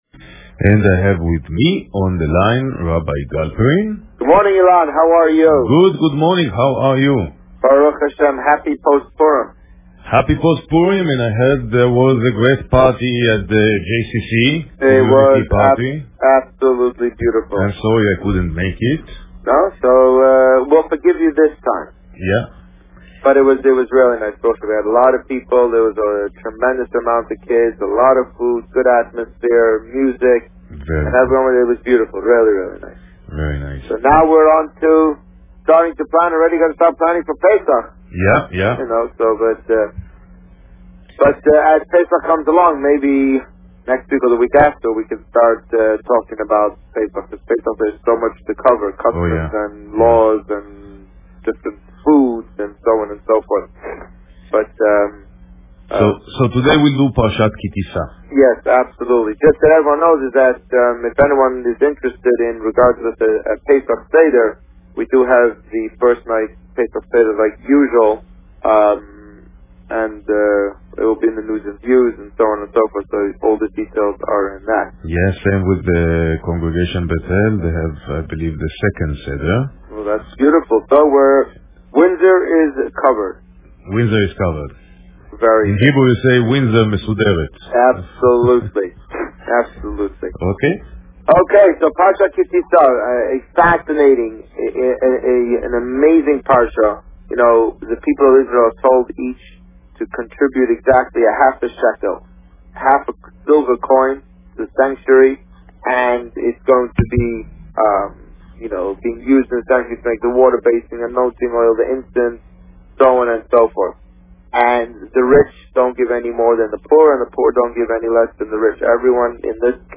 Parsha Ki Tisa and Post Purim report Published: 28 February 2013 | Written by Administrator This week, the Rabbi spoke about Parsha Ki Tisa, the aftermath of the Purim party and plans for the upcoming Pesach holiday. Listen to the interview here .